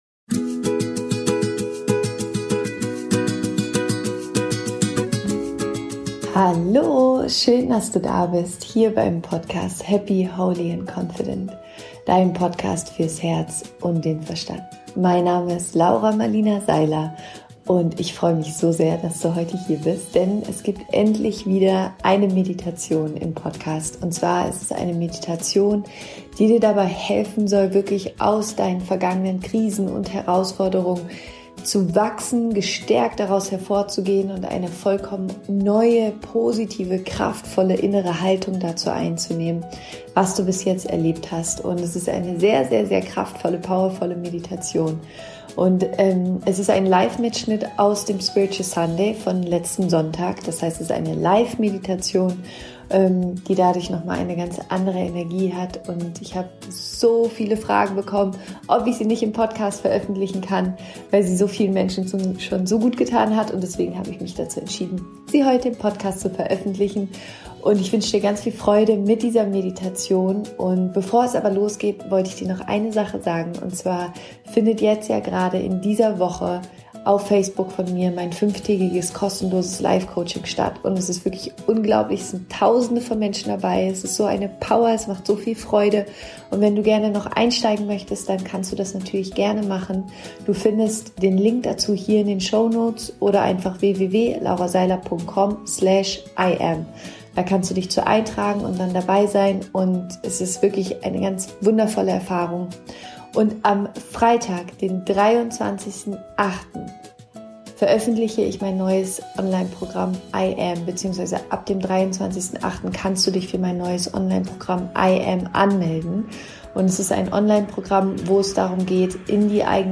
Diese Meditation ist ein Mitschnitt aus dem Spiritual Sunday von letzten Sonntag und da sie live ist, hat sie noch einmal eine ganz andere, wundervolle Energie.